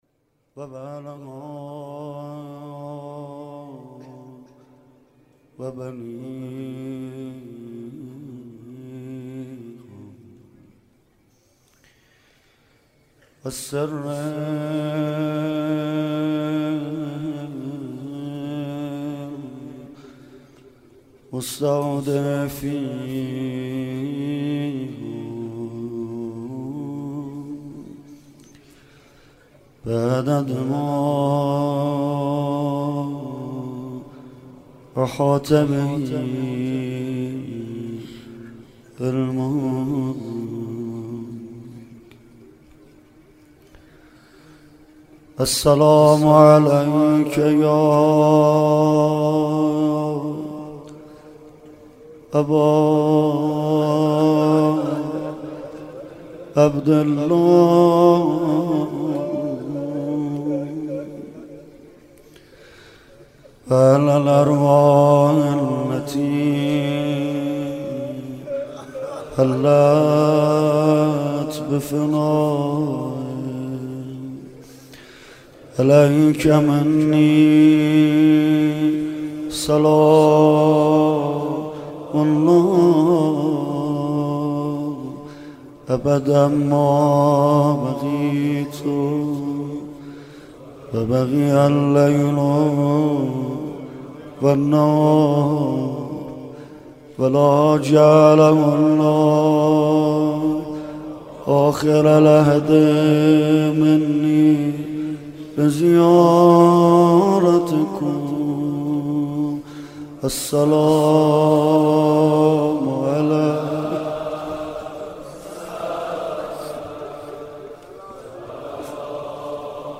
روضه حضرت علی اکبر